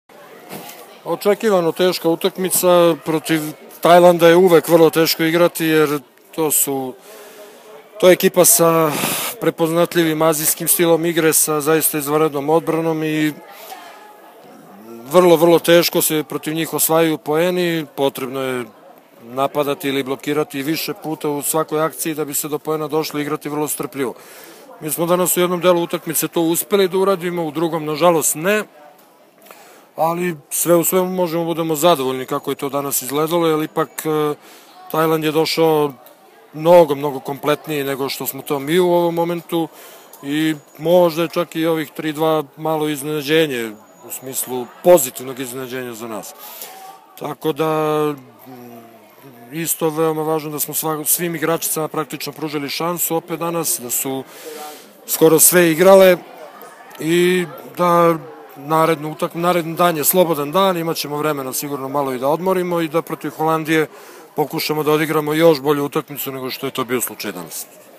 IZJAVA ZORANA TERZIĆA